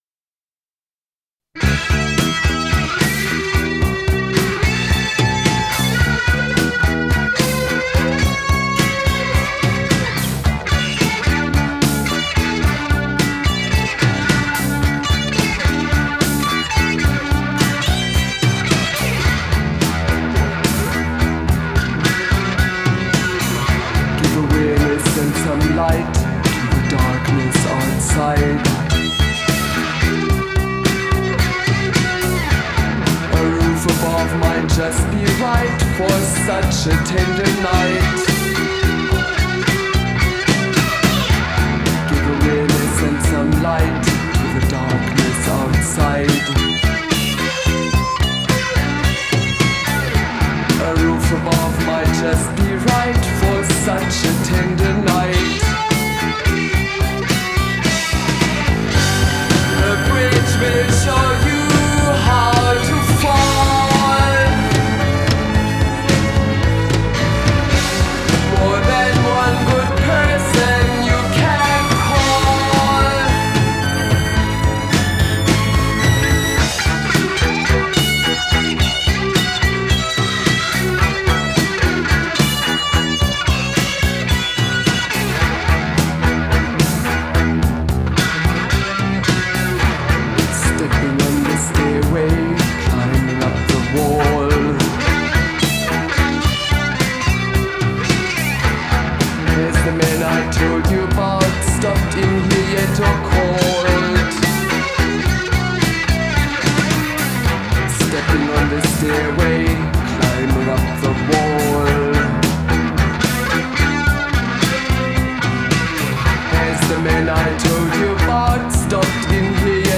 post punk Art Rock Experimental Rock